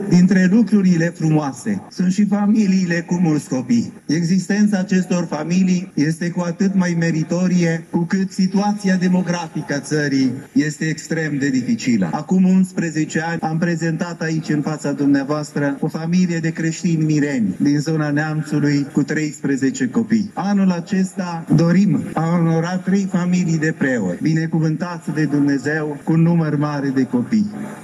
Ceremonialul s-a desfășurat, ca în fiecare an, pe un podium special amenajat pe pietonalul Ștefan cel Mare și Sfânt, în dreptul Catedralei Mitropolitane.